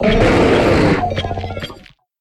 Cri de Roc-de-Fer dans Pokémon HOME.